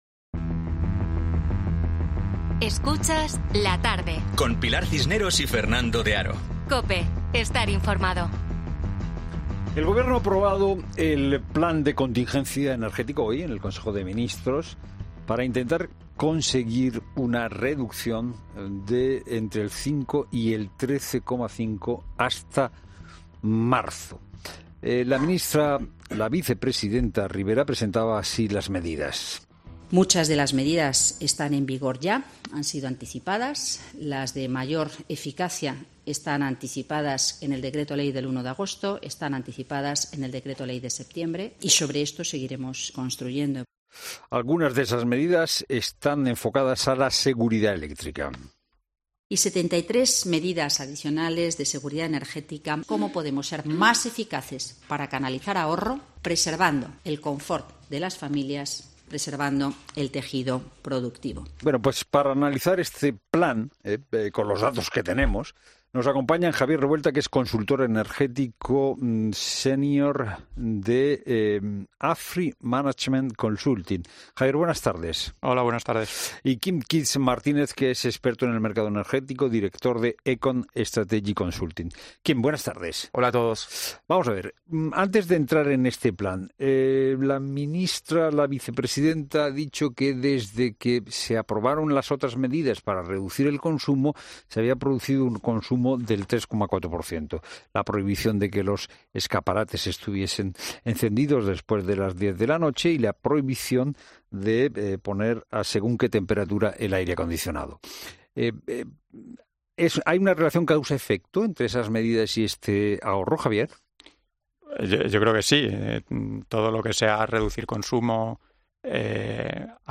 Por ello, en La Tarde de COPE dos expertos en el mercado energético han dado claves a los oyentes sobre qué pueden hacer para frenar la escalada en el precio de la factura.